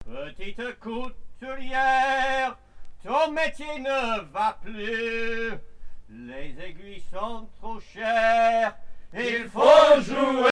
- 007415 Thème : 0074 - Divertissements d'adultes - Couplets à danser Résumé : Pour qui cousez-vous, petite couturière, pour qui cousez-vous ?
circonstance : maritimes ; gestuel : travail ;
Pièce musicale éditée